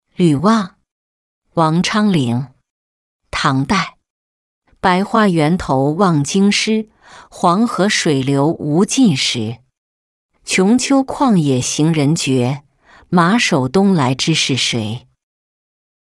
旅望-音频朗读